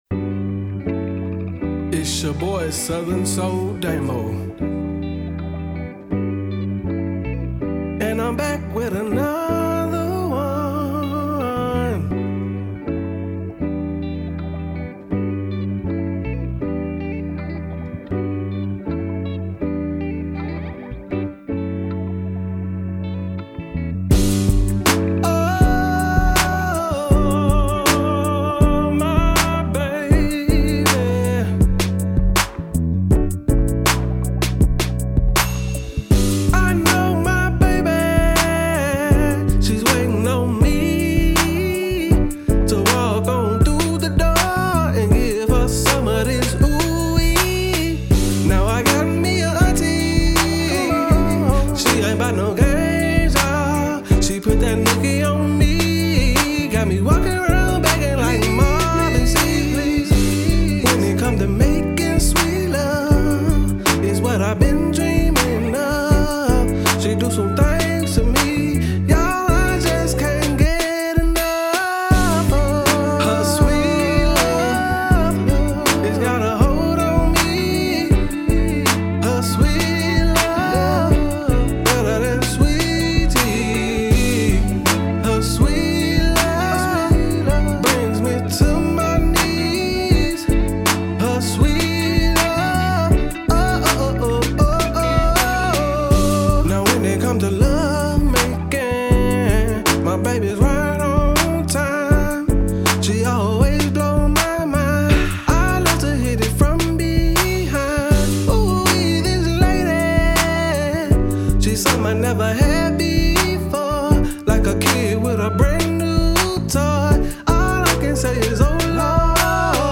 Smooth vocals, soulful rhythm, and grown-folk vibes.